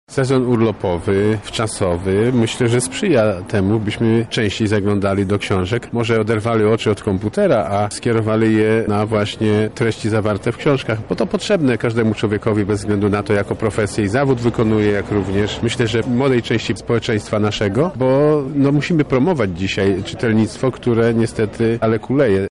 – mówi marszałek Sławomir Sosnowski.